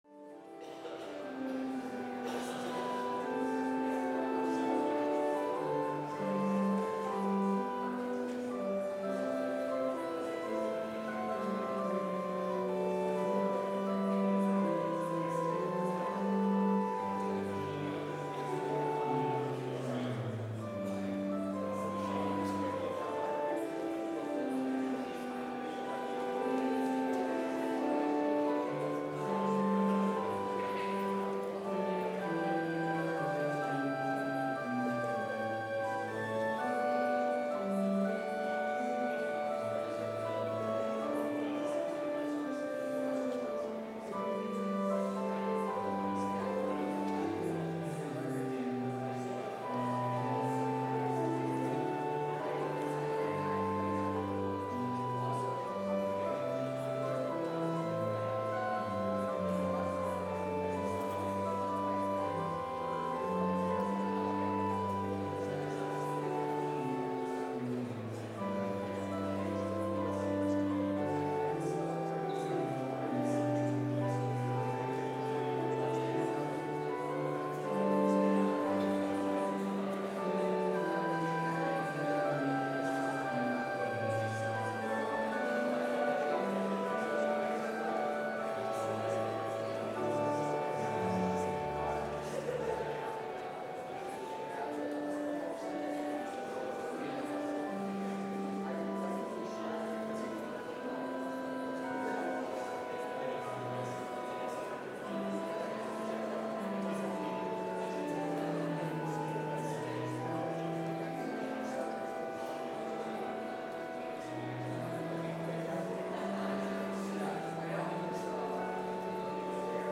Complete service audio for Chapel - Wednesday, May 8, 2024